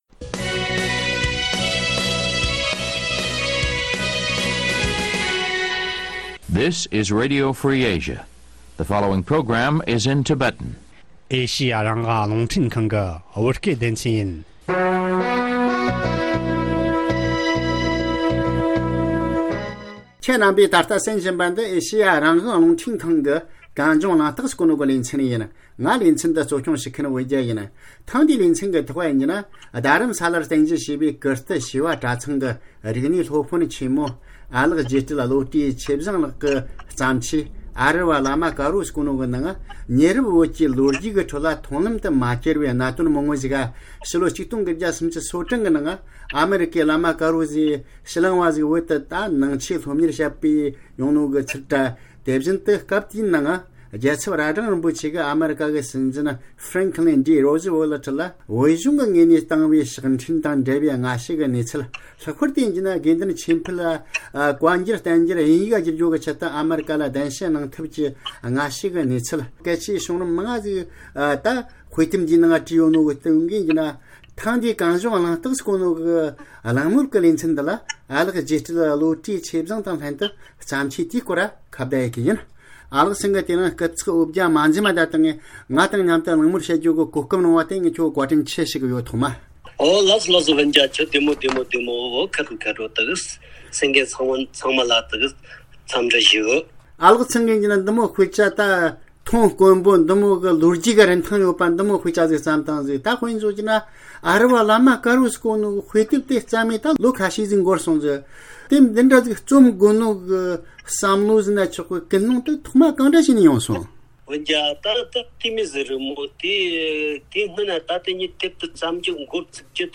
གླེང་མོལ་ཞུས།